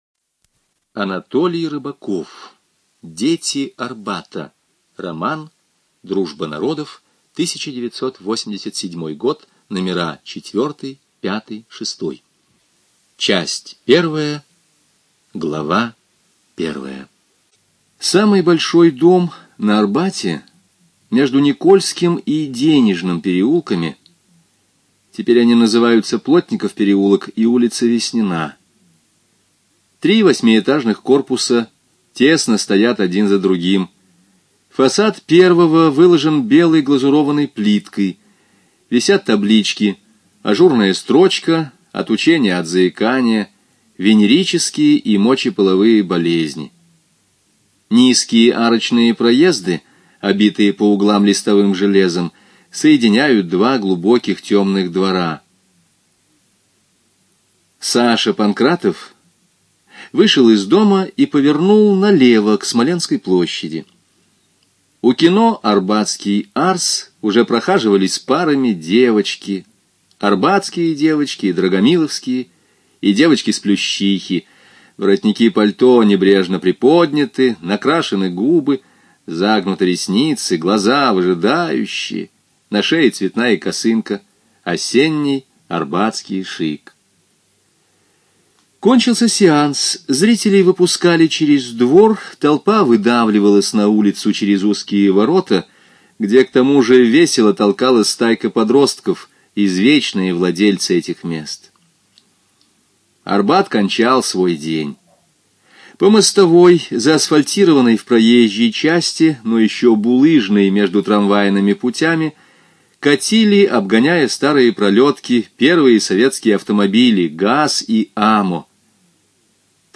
ЖанрСовременная проза
Студия звукозаписиЛогосвос